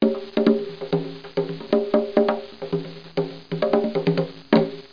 00723_Sound_conga.mp3